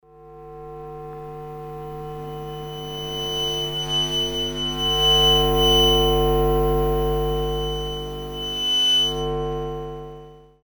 In order to acoustically illustrate the sounds of public space that cannot be heard by human ears but can be registered with help of special equipment, for the beginning as a short introduction to the topic, following sounds were recorded in Ljubljana:
Self-opening doors sensor in Ljubljana
selfopening_doors_sensor_ljubljana.mp3